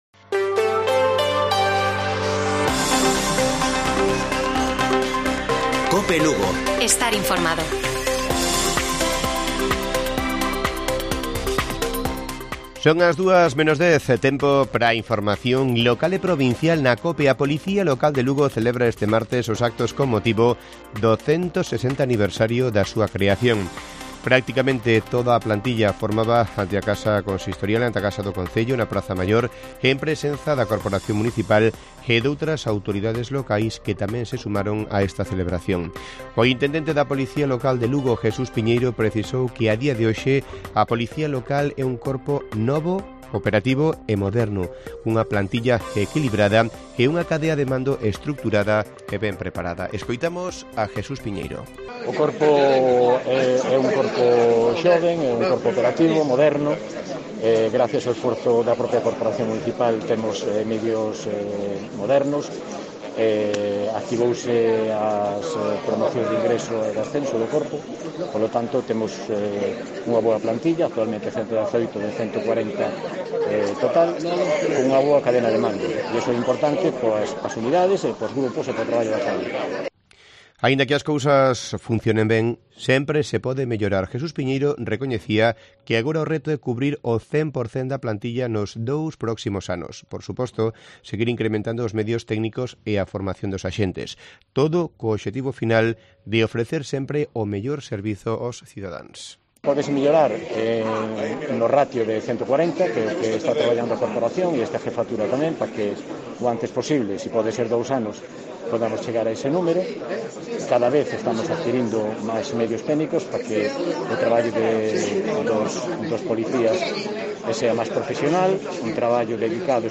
Informativo Mediodía de Cope Lugo. 24 DE MAYO. 13:50 horas